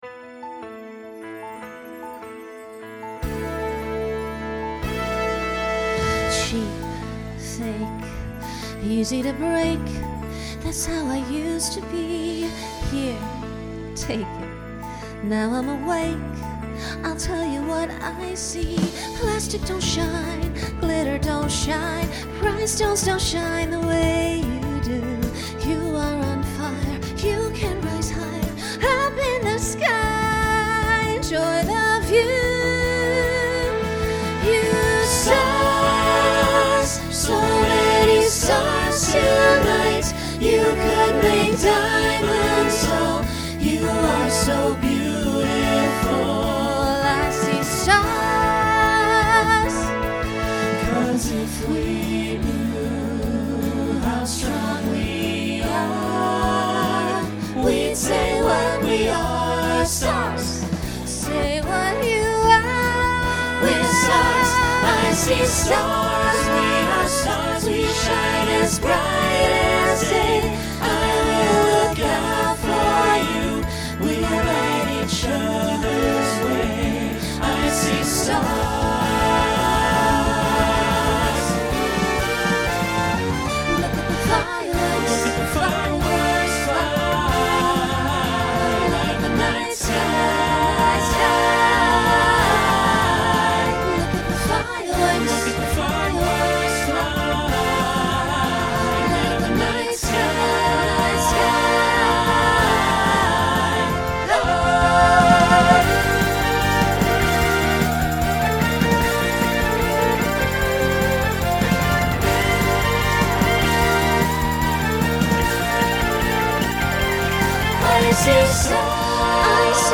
Genre Broadway/Film Instrumental combo
Voicing SATB